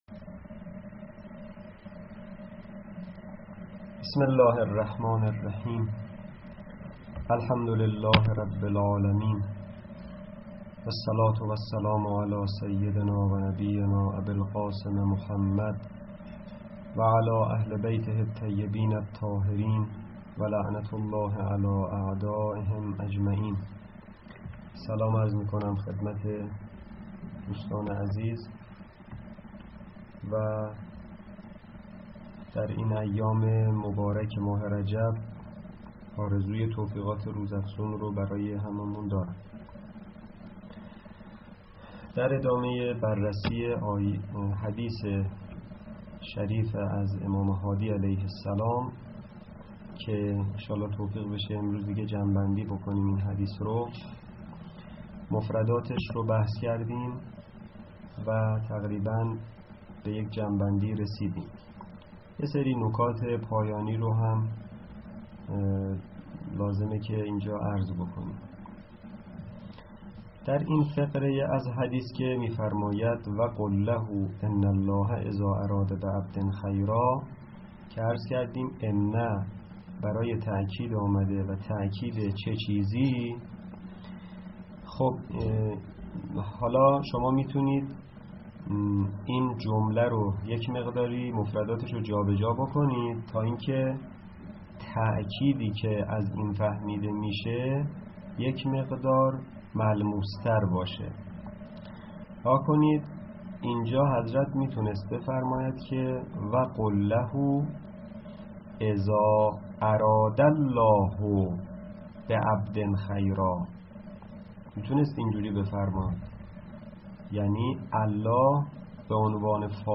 در این بخش، صوت و یا فیلم سخنرانیهای علمی، گفتگوهای علمی، نشست علمی، میزگردها و مناظرات علمی مرتبط با دروس حوزوی درج می‌گردد.